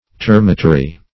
termatary - definition of termatary - synonyms, pronunciation, spelling from Free Dictionary Search Result for " termatary" : The Collaborative International Dictionary of English v.0.48: Termatary \Ter"ma*ta*ry\, n. (Zool.)